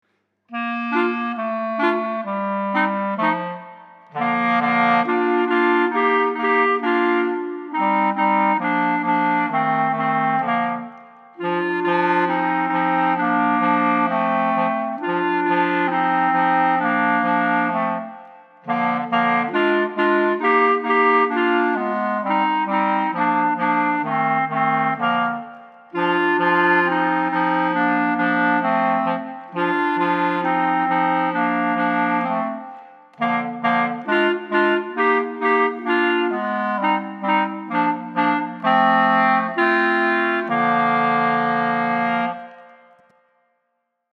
easy to play trios